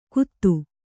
♪ kuttu